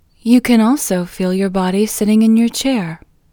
QUIETNESS Female English 3